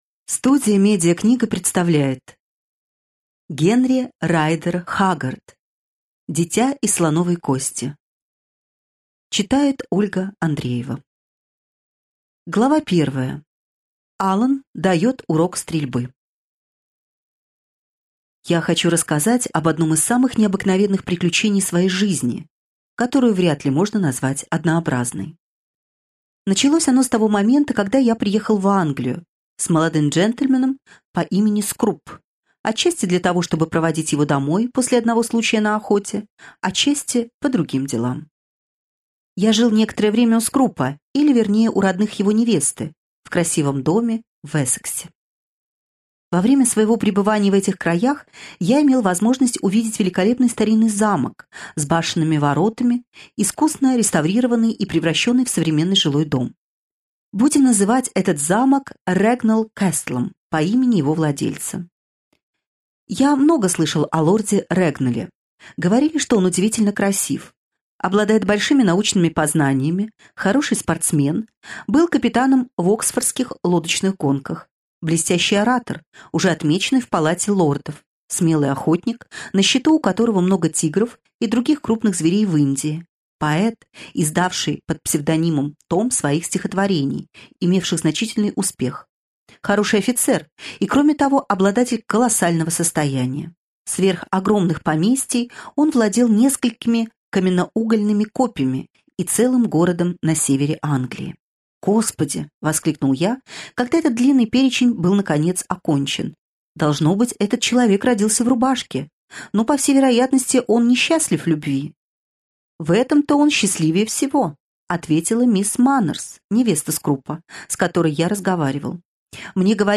Аудиокнига Дитя из слоновой кости | Библиотека аудиокниг